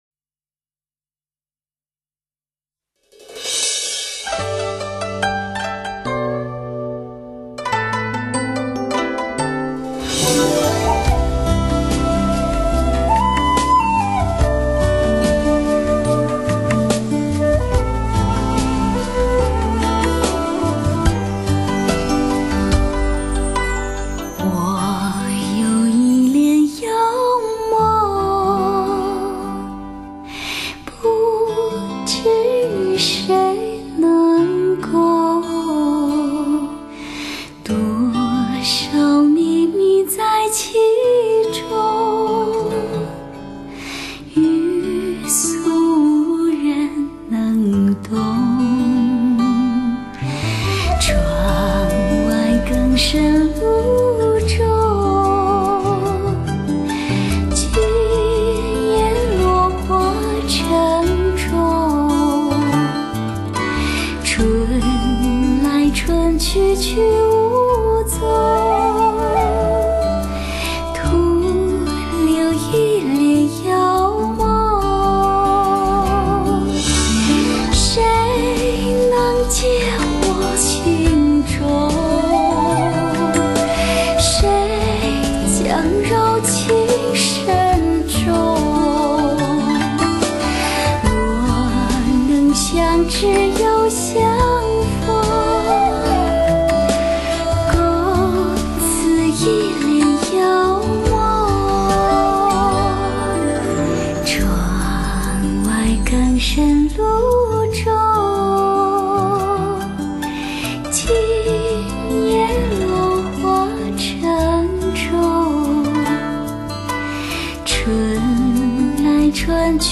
如水般的旋律 清澈的声音 透过河流 穿越山川 流进你我的心田